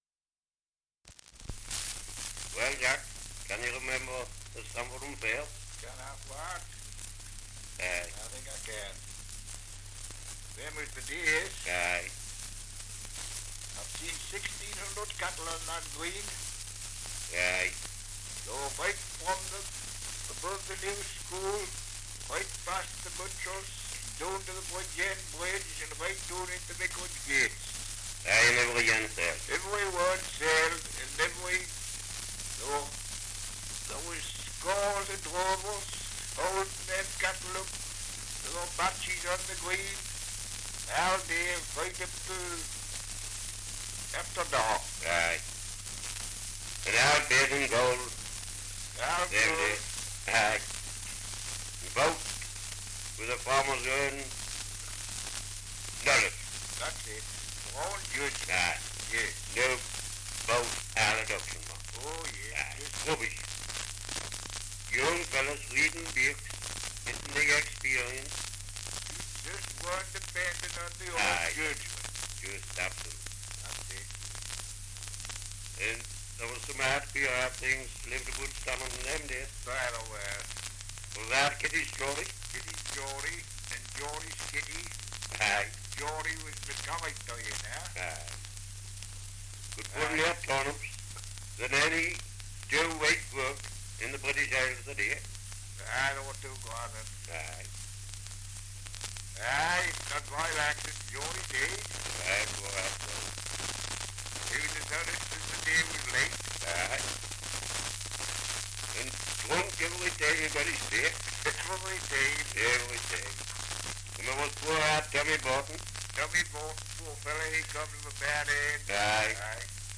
2 - Dialect recording in Stamfordham, Northumberland
78 r.p.m., cellulose nitrate on aluminium